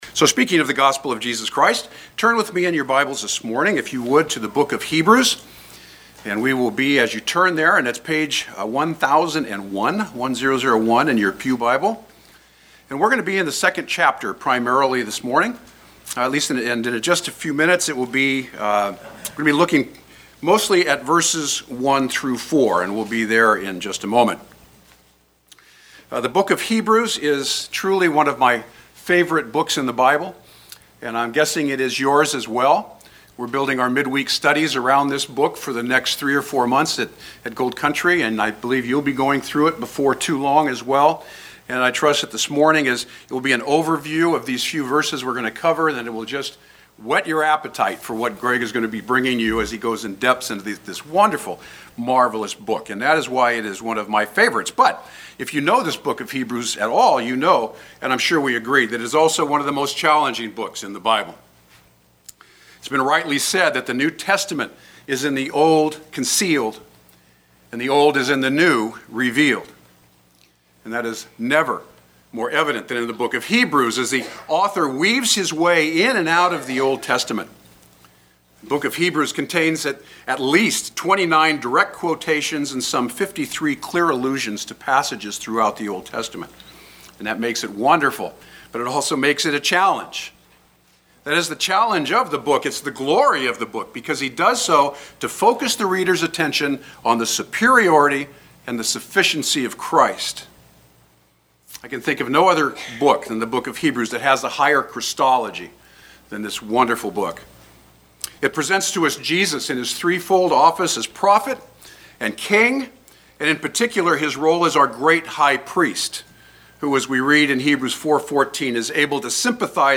Do Not Drift Guest Preacher